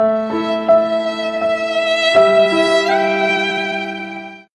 Fx Pena Violin Sound Button - Free Download & Play